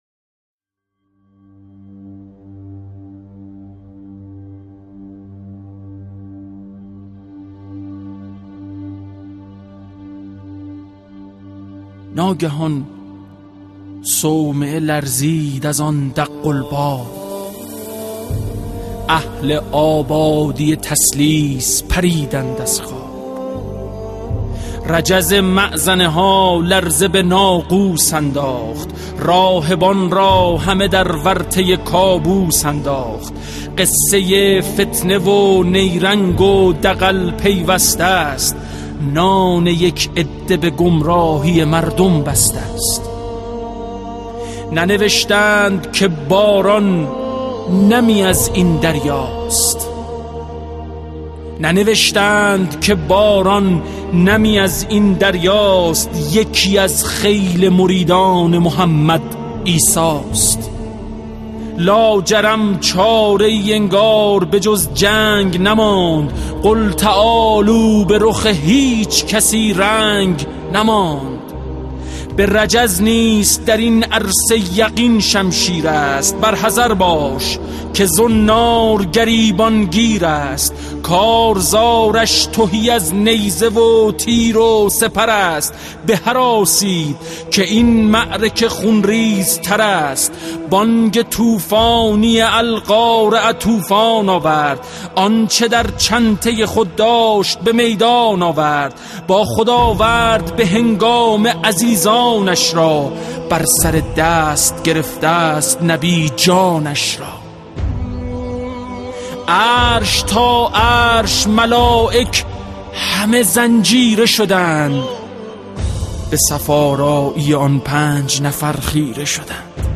شعرخوانی حمیدرضا برقعی به مناسبت روز مباهله؛
شعر «مهر رسولانه» با صدای حمیدرضا برقعی